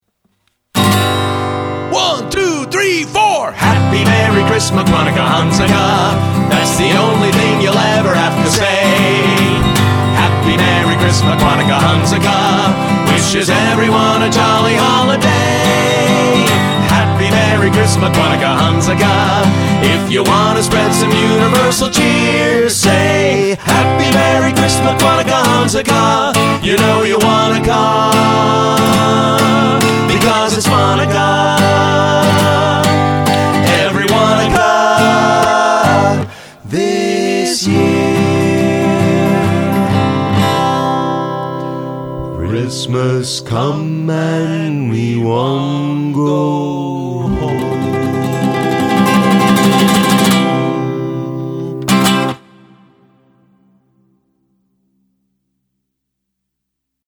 Rehearsal Audio